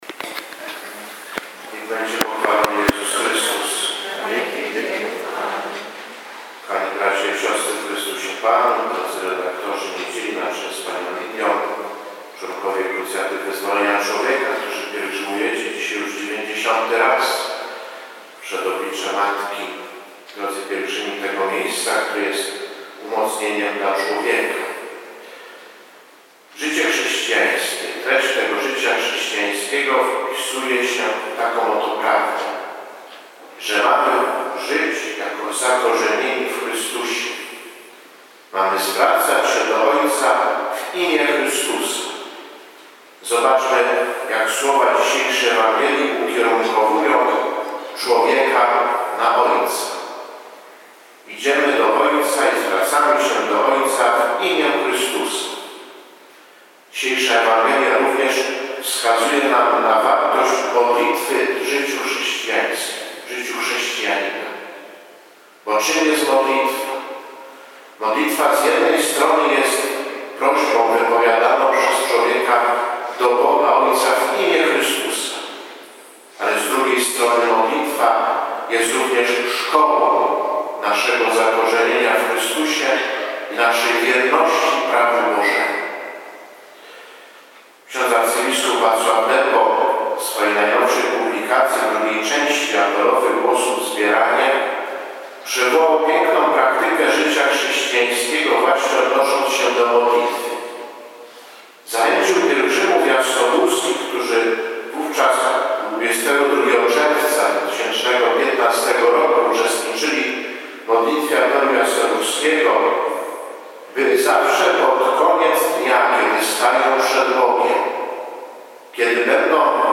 W każdą pierwszą sobotę miesiąca w Kaplicy Cudownego Obrazu Matki Bożej na Jasnej Górze o godz. 7.30 odprawiana jest Msza św. w intencji tygodnika katolickiego „Niedziela”, pracowników, redaktorów i dzieł tygodnika.
kazanie.MP3